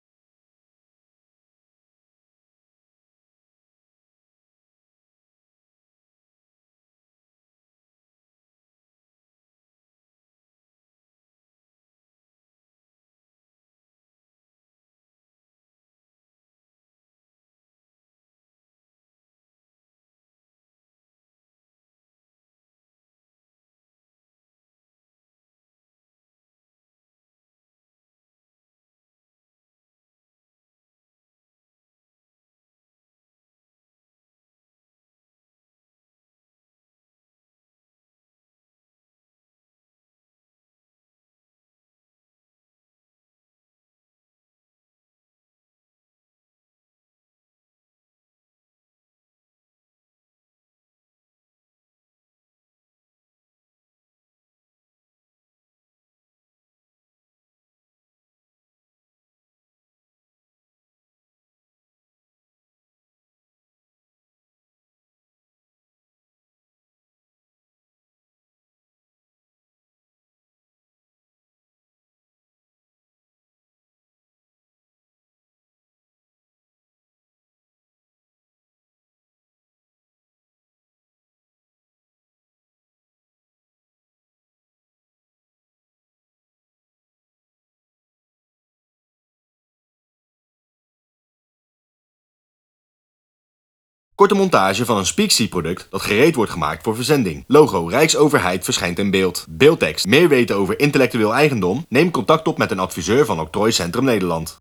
*Funky muziek begint*
*Up-tempo muziek start*